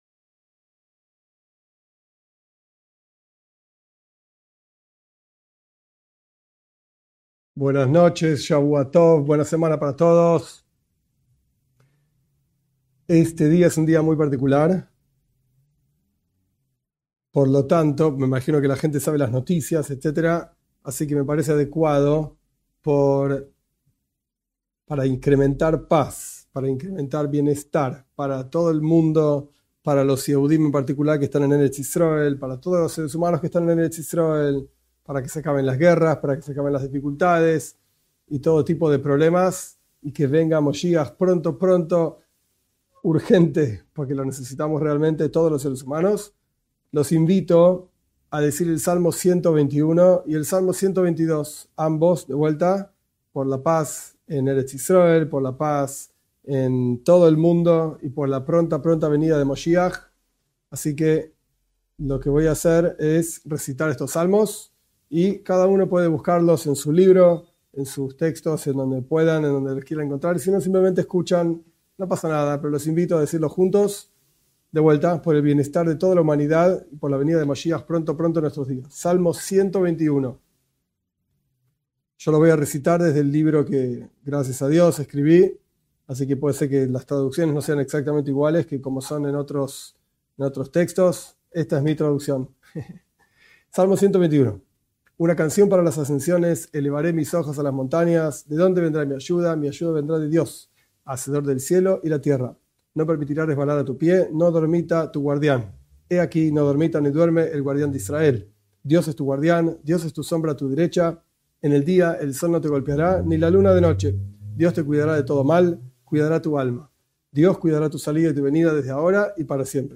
En este curso estudiaremos los preceptos del judaísmo en forma breve, basándose en el libro de la educación (Sefer HaJinuj) y aplicándolos a Bnei Noaj de acuerdo a los escritos de Rabí Ionatan Steiff (1877-1958). En esta clase estudiamos los preceptos relacionados a los Diez Mandamientos.